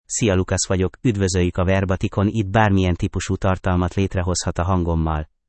LucasMale Hungarian AI voice
Lucas is a male AI voice for Hungarian (Hungary).
Voice sample
Listen to Lucas's male Hungarian voice.
Lucas delivers clear pronunciation with authentic Hungary Hungarian intonation, making your content sound professionally produced.